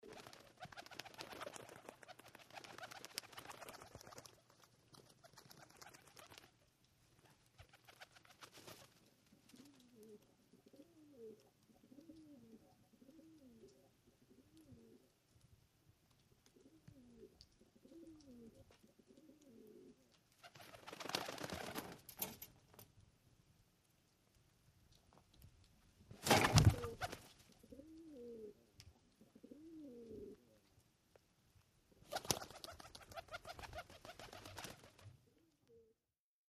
Pigeon wings fluttering & cooing